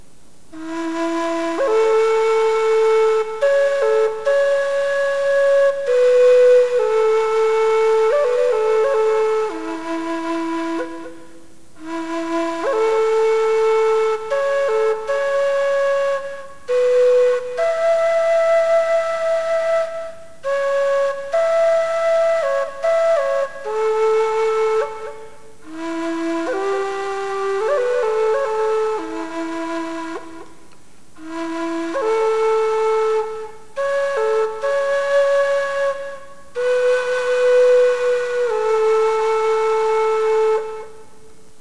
There are several places in the piece where I've altered the timing in order to make the file a little smaller for quicker download.